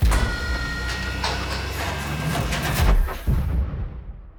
push.wav